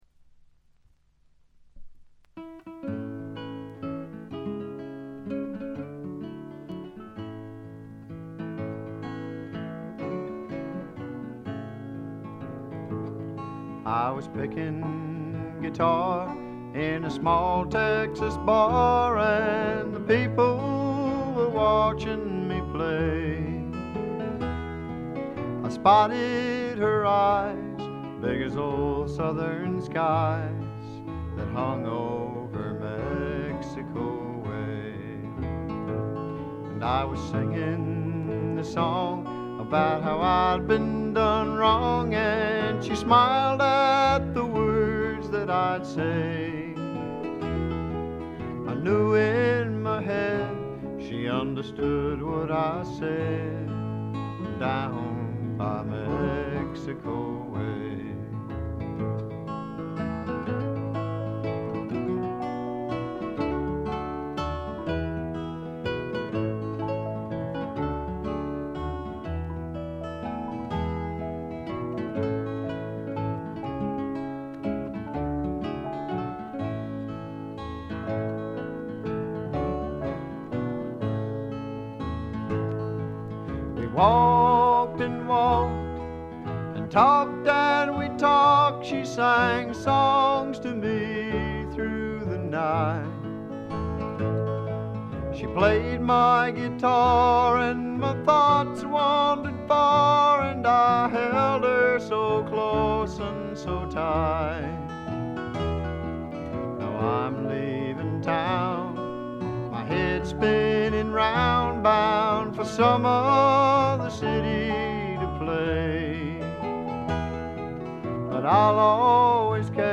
ほとんどノイズ感無し。
内容的にも前作路線で弾き語りに近いシンプルなバックのみで歌われる全14曲。
ロンサムで暖かい空気が部屋の中で静かに満ちていくような感覚がたまらないです。
試聴曲は現品からの取り込み音源です。